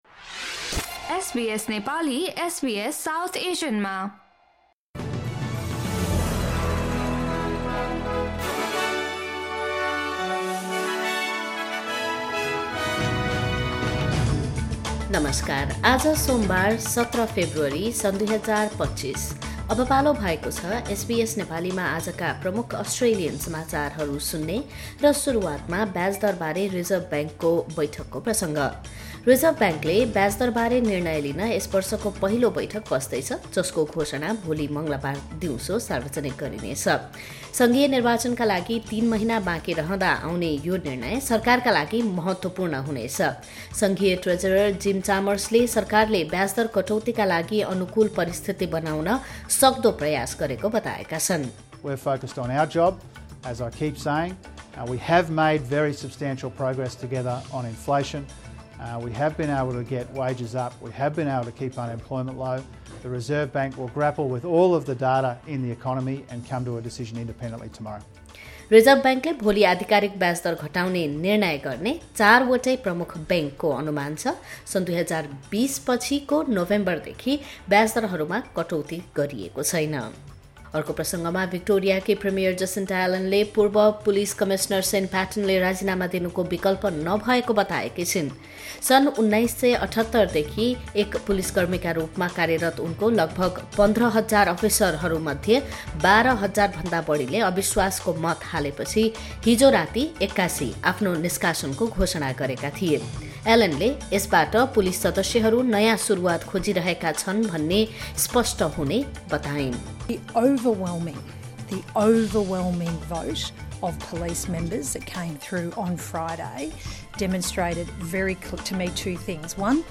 SBS Nepali Australian News Headlines: Monday, 17 February 2025